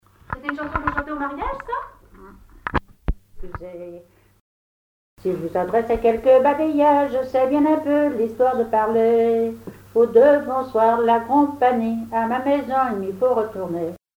circonstance : fiançaille, noce
Chansons traditionnelles et populaires
Pièce musicale inédite